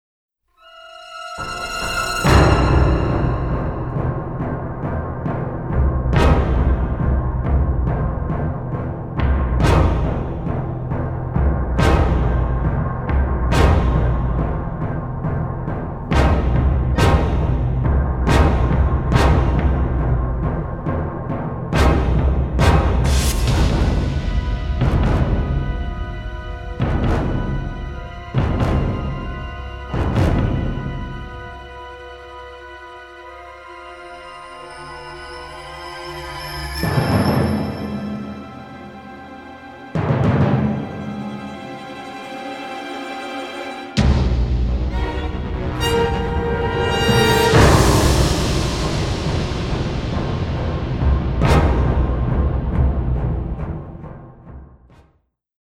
hip-hop
to English electronica
synthesizers, orchestra and choir
an unusually subtle yet powerful and dramatic score.